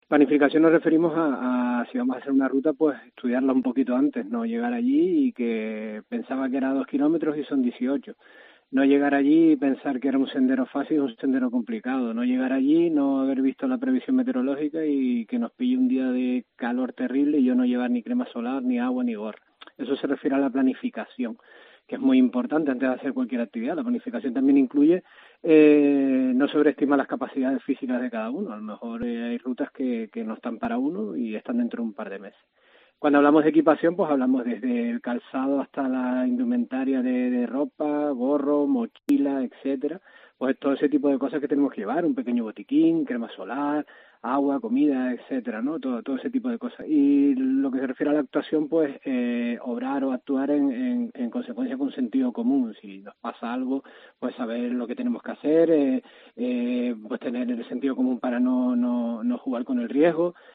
guía de montaña profesional